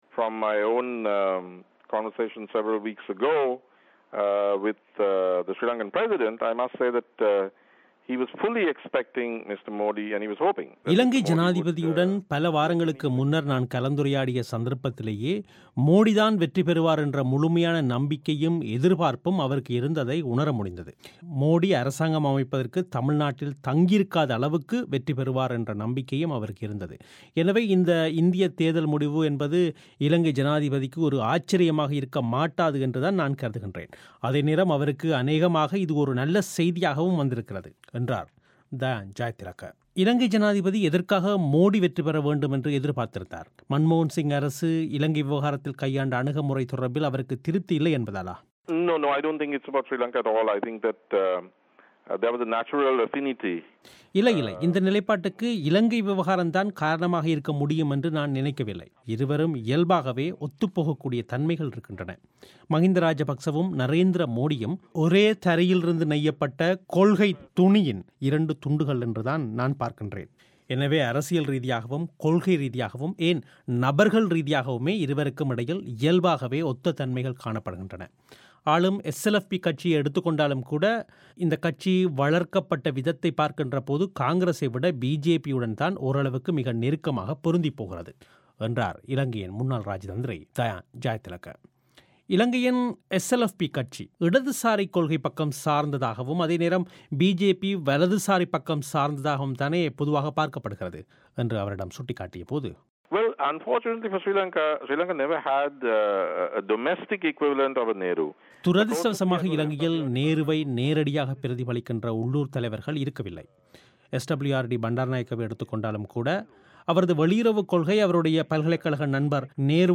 நரேந்திர மோடி தமிழகத்தின் துணையின்றி ஆட்சியமைப்பார் என்று இலங்கை ஜனாதிபதி நம்பிக்கையோடு எதிர்பார்த்திருந்ததாக இலங்கையின் முன்னாள் இராஜதந்திரி தயான் ஜயதிலக்க பிபிசி தமிழோசையிடம் கூறினார்.